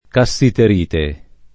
[ ka SS iter & te ]